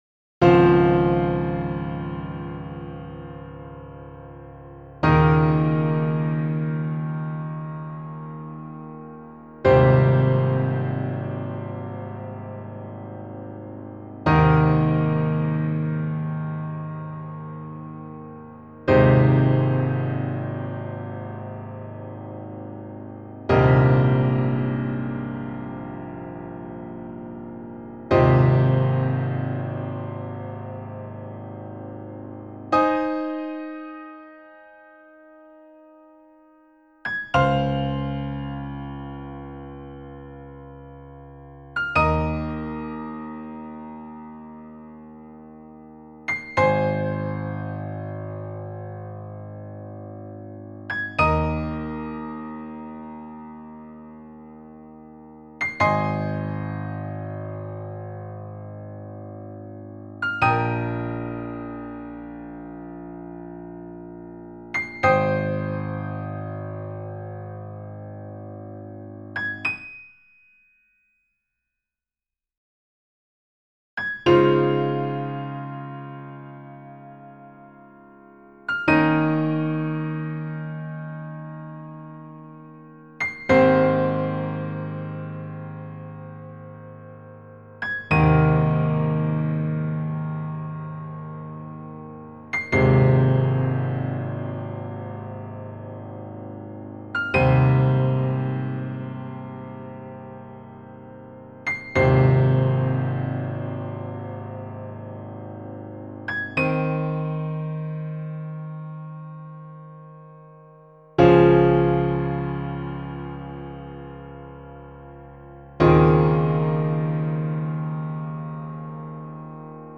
ピアノの1拍子の曲です
こういう独奏は、リバーブ無い方がいいですね